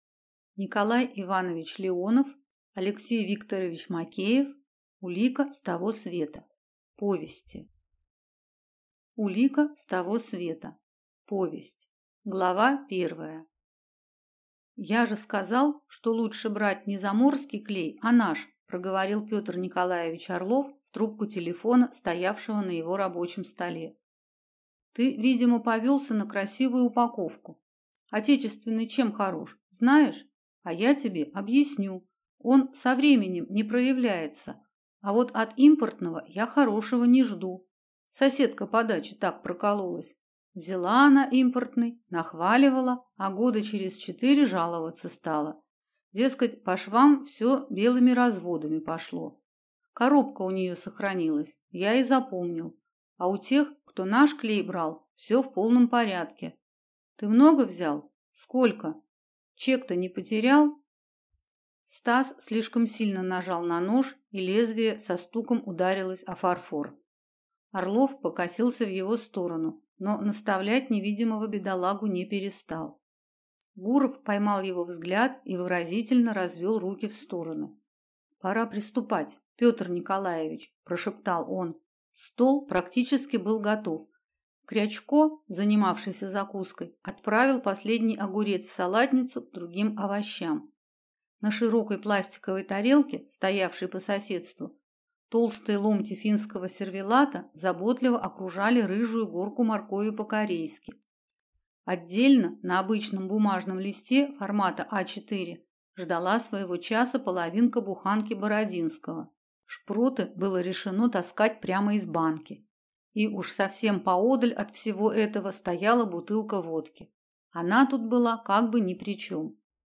Аудиокнига Улика с того света | Библиотека аудиокниг
Прослушать и бесплатно скачать фрагмент аудиокниги